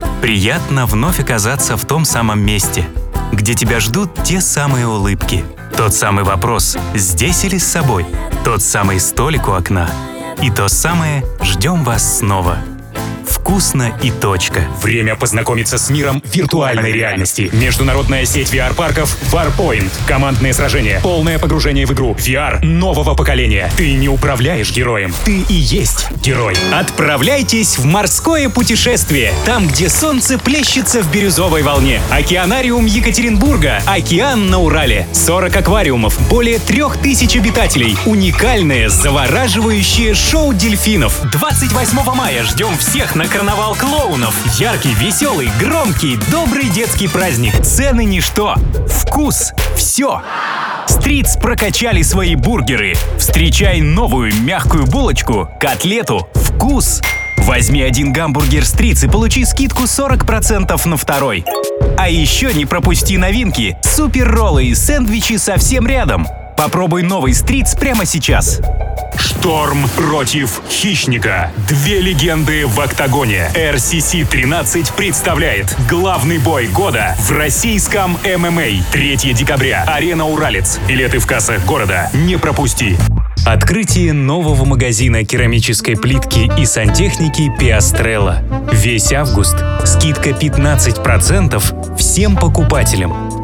Пример звучания голоса
Муж, Рекламный ролик/Средний